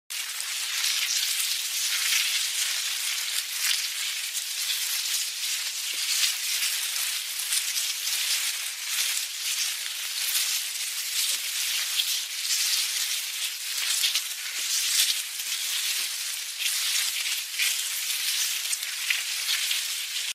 Колония муравьев